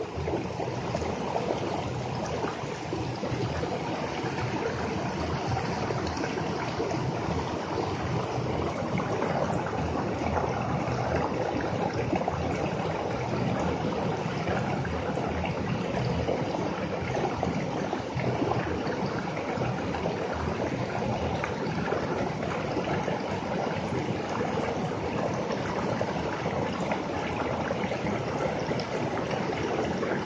描述：SonidodelRíoPanceen los Farallones de Cali。